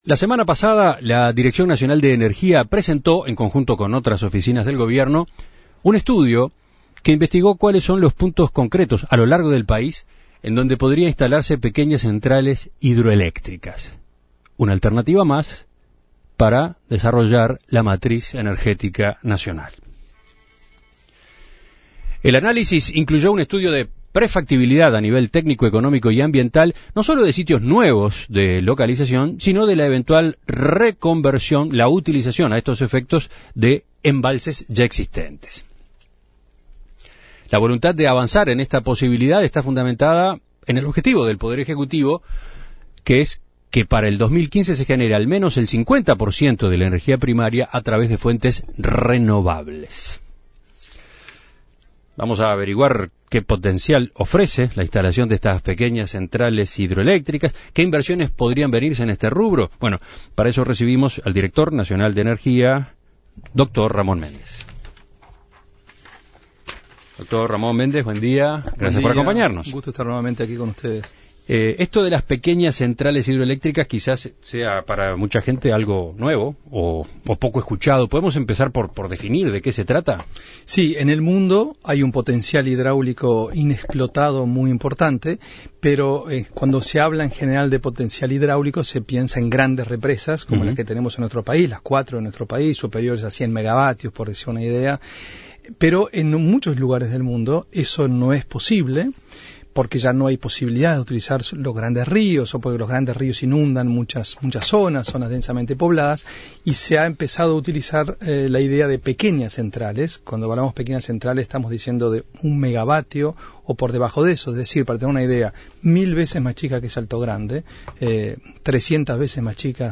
La Dirección Nacional de Energía (DNE) realizó una investigación que enumera varios puntos del país en donde se pueden instalar pequeñas centrales hidroeléctricas que permitirán el mejor aprovechamiento de los recursos naturales de nuestro país. En entrevista con En Perspectiva, Ramón Méndez, director nacional de Energía, reiteró el objetivo de la cartera de querer seguir avanzando hacia las energías renovables.